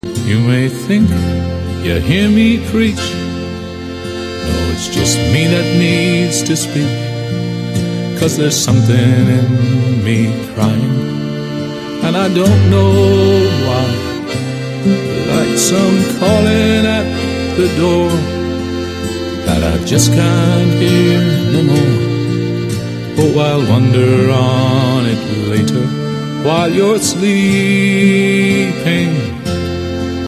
bass guitar
Uilleann pipes.